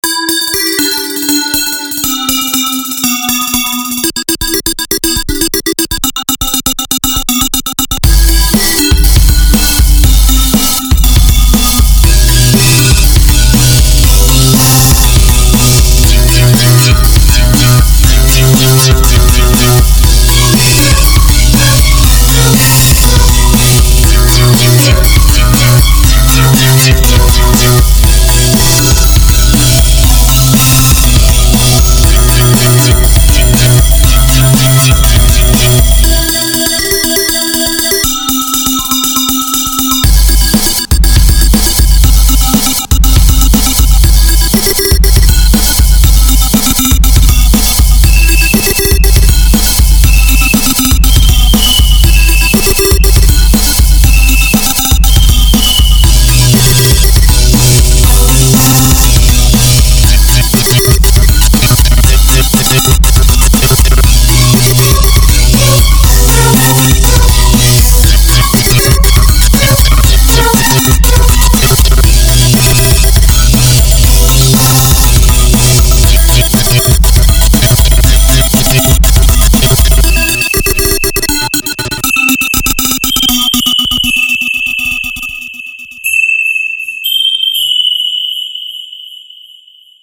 dark